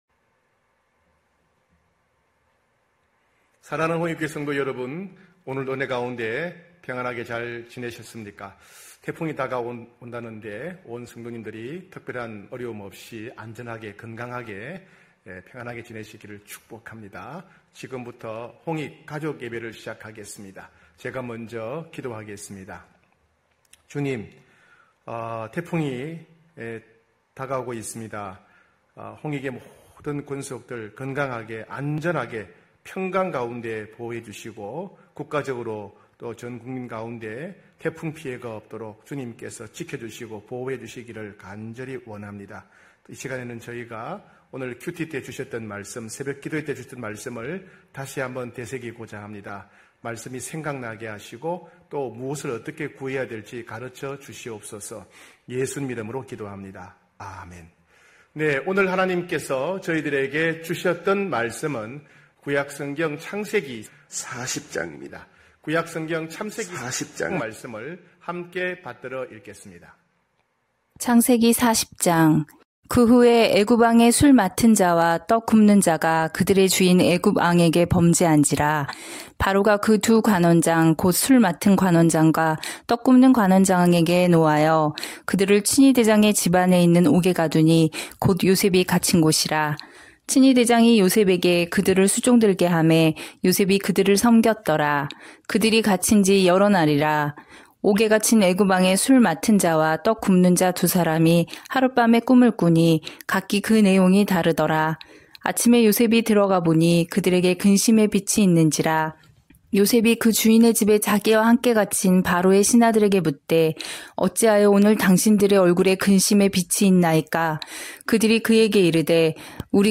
9시홍익가족예배(9월5일).mp3